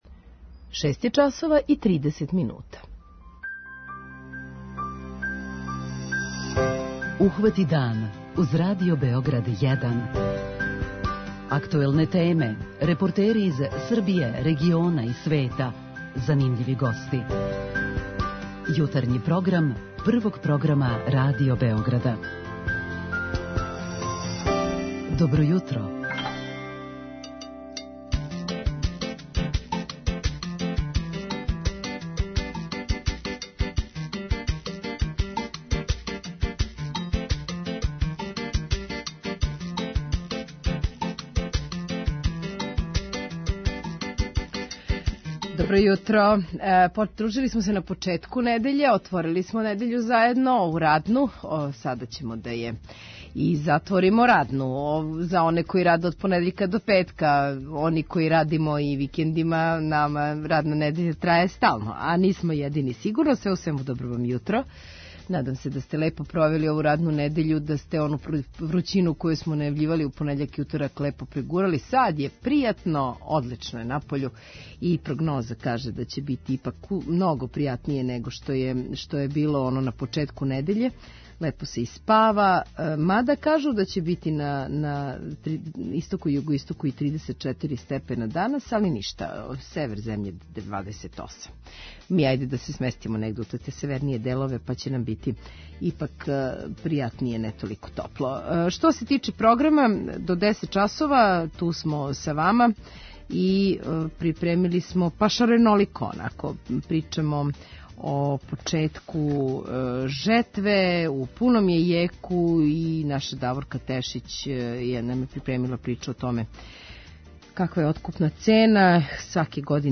преузми : 37.72 MB Ухвати дан Autor: Група аутора Јутарњи програм Радио Београда 1!